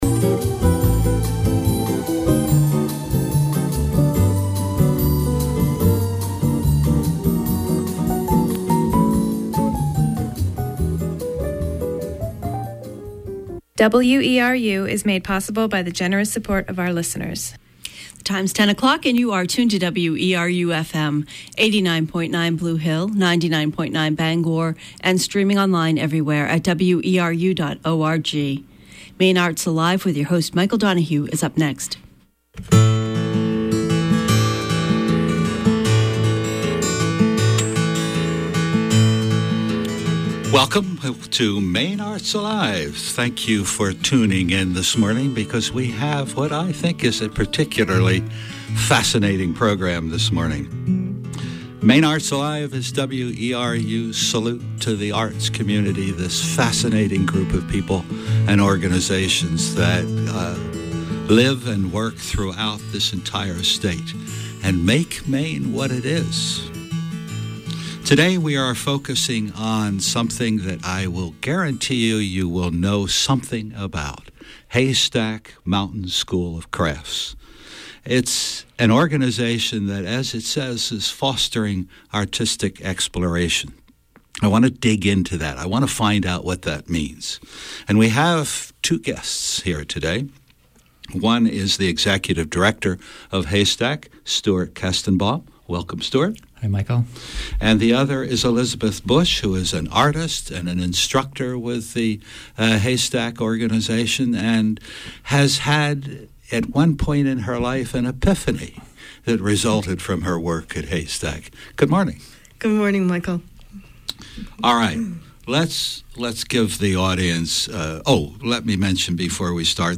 Call-In Program